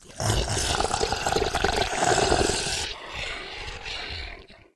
sounds / monsters / psysucker / idle_3.ogg